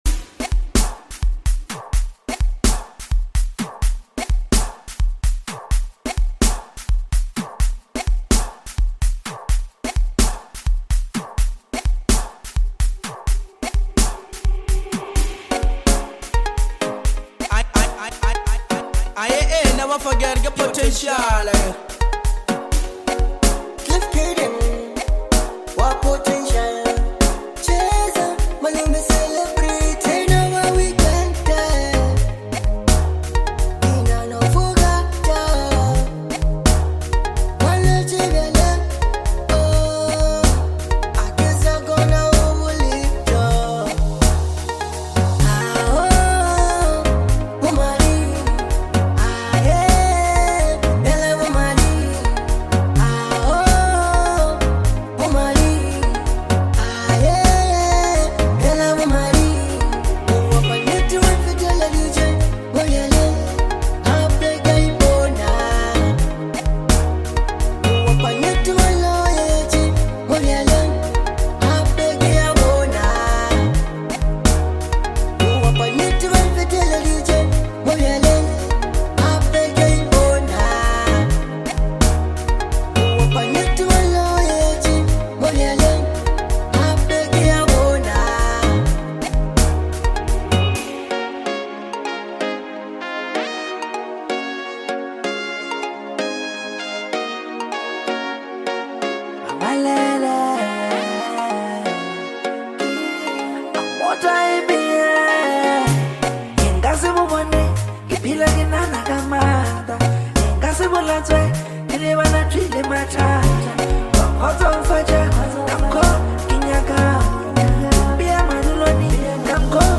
The vibrant track